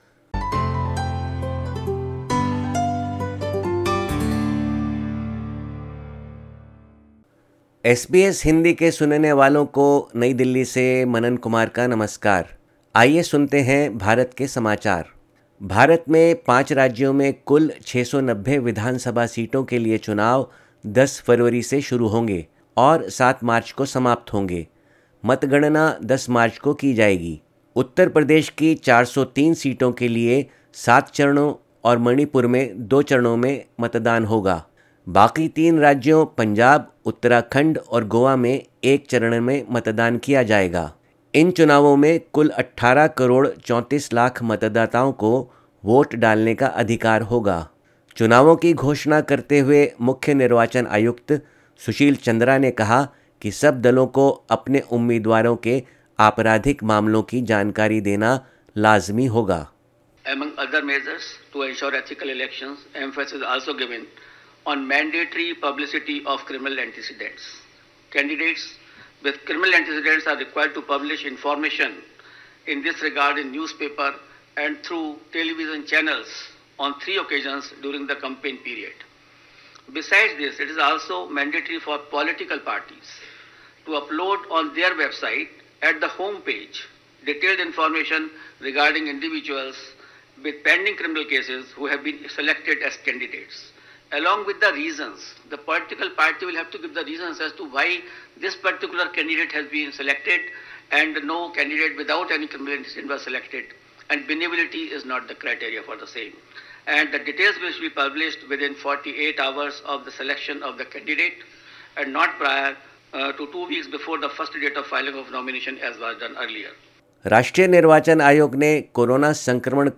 भारत के समाचार हिन्दी में